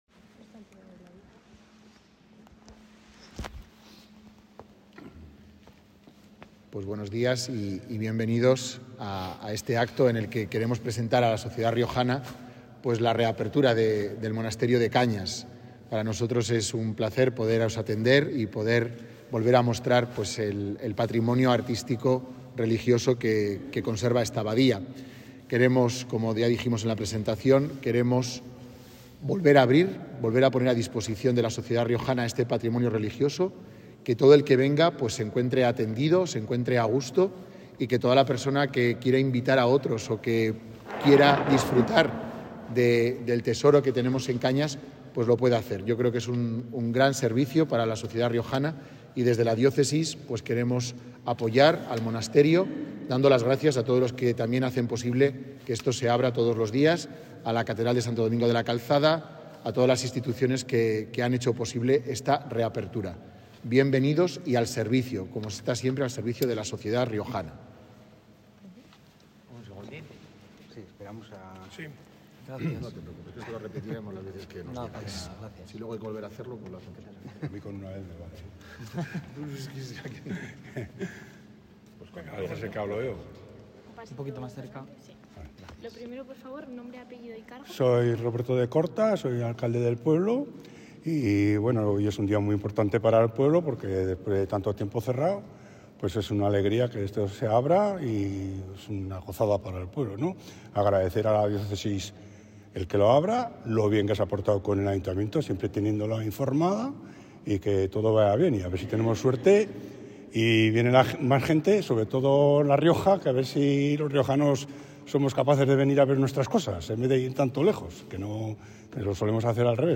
Audio-visita-Canas.mp3